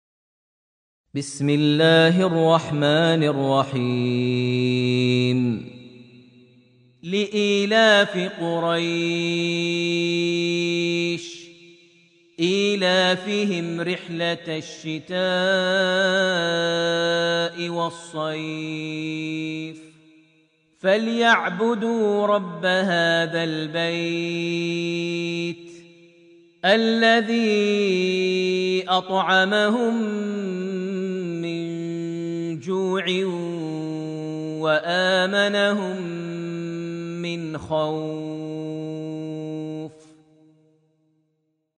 Surat Quraish > Almushaf > Mushaf - Maher Almuaiqly Recitations